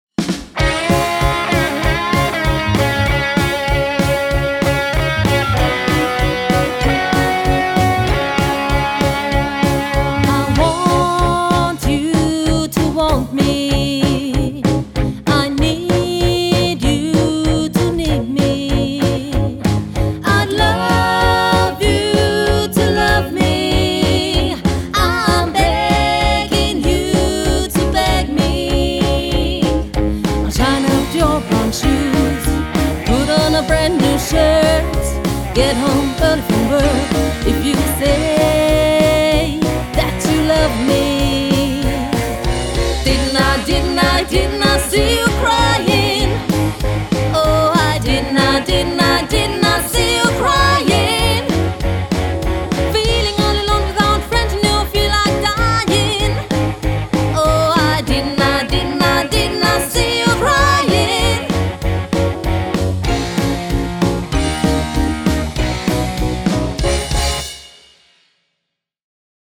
Quartett oder Quintett